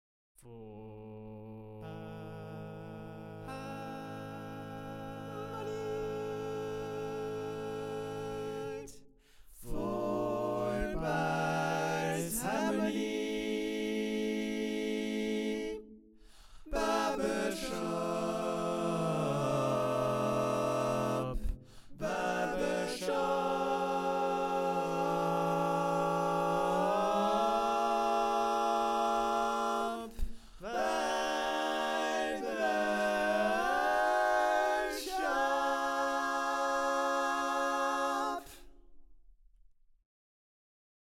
How many parts: 4
Four Part Harmony
All Parts mix:
Learning tracks sung by